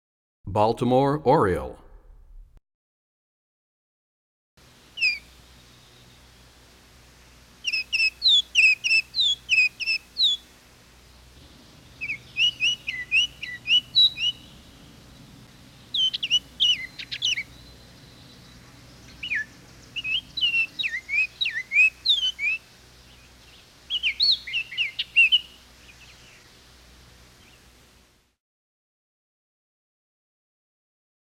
05 Baltimore Oriole.mp3